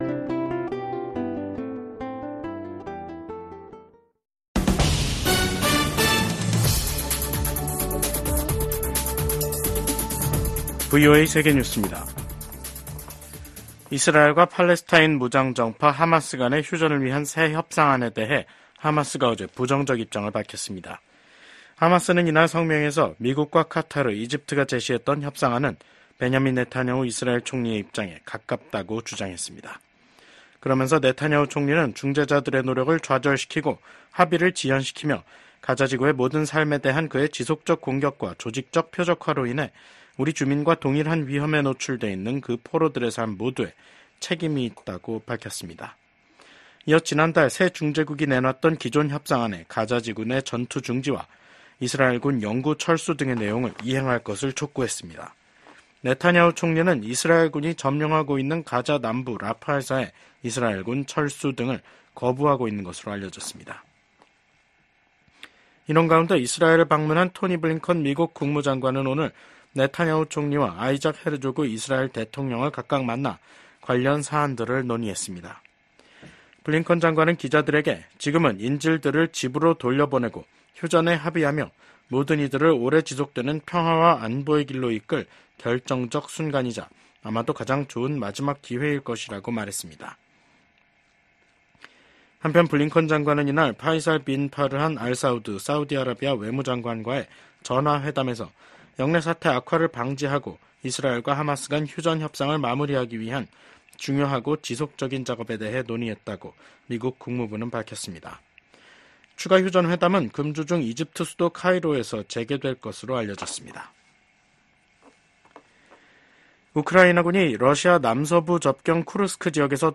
세계 뉴스와 함께 미국의 모든 것을 소개하는 '생방송 여기는 워싱턴입니다', 2024년 8월 19일 저녁 방송입니다. 정∙부통령 후보를 지명하기 위한 민주당 전당대회가 19일 시카고에서 시작합니다. 현재 진행 중인 팔레스타인 가자지구 휴전 협상이 마지막 기회일 수 있다고 토니 블링컨 미국 국무장관이 밝혔습니다. 엠폭스가 확산 함에 따라 세계보건기구(WHO)가 국제적 공중보건 비상사태(PHEIC)를 선언한 데 이어 유럽과 중국에서도 방역 조처가 강화됐습니다.